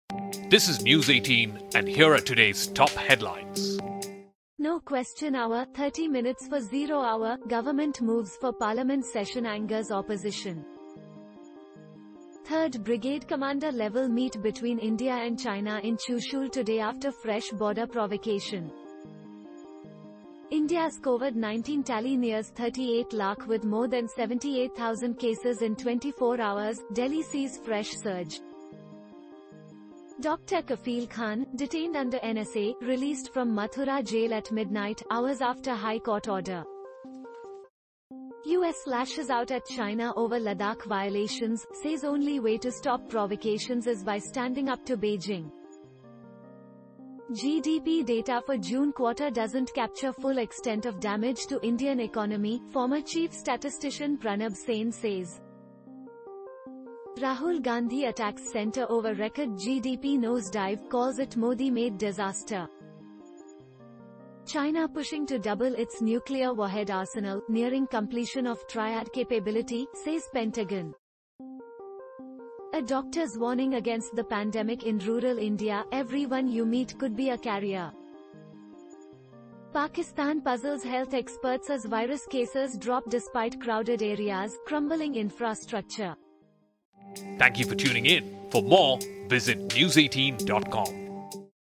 Catch up with the top headlines of the day with our Audio Bulletin, your daily news fix in under 2 minutes.